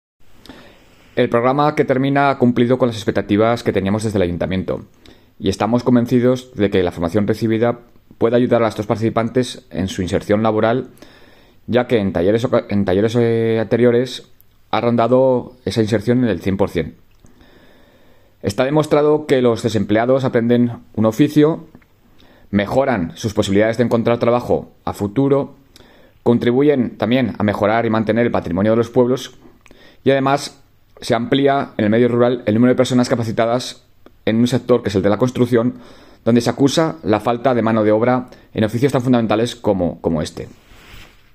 Declaraciones-Enrique-Pueyo-ultimo-programa-experiencial-en-Ainsa.mp3